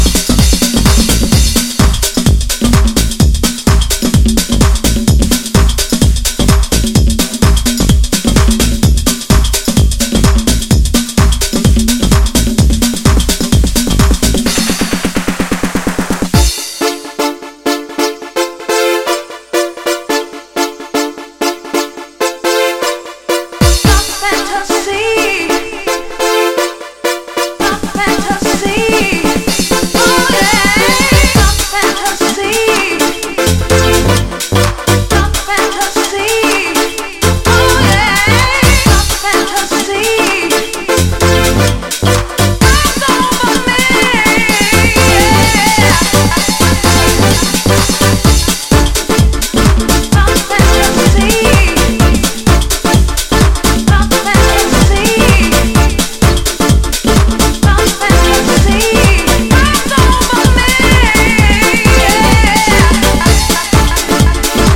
- компрессия + увеличенная амплитуда:
Последний вариант громче всех.
comp+limit.mp3